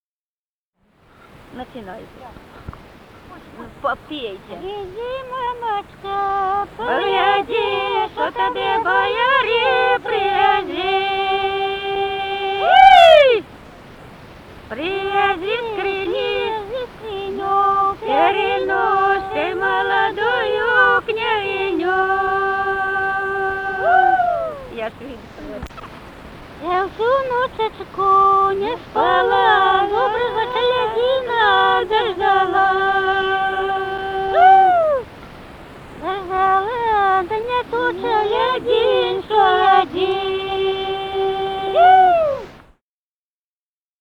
Музыкальный фольклор Климовского района 016. «Выйди, мамочка, погляди» (свадьбишная).
Записали участники экспедиции